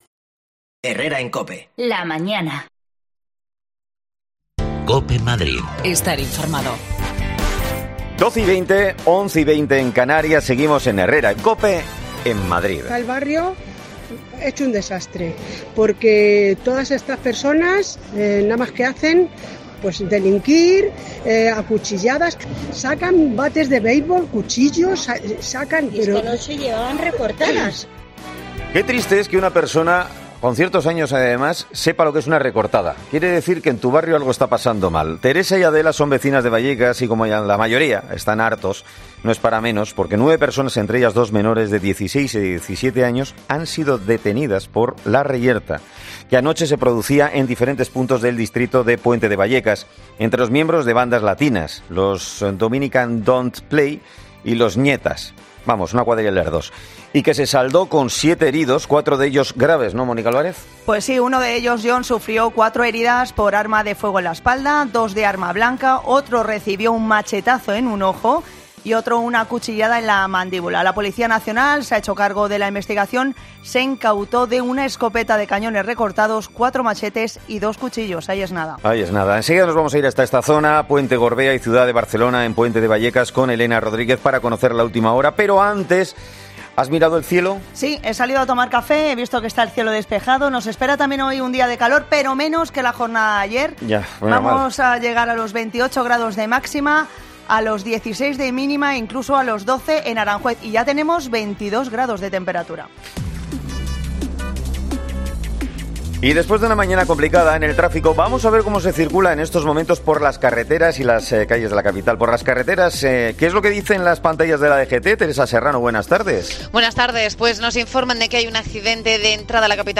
Pelea entre bandas rivales latinas deja un saldo de 9 detenidos en Puente de Vallecas. Hablamos con los vecinos que están hartos de la situación.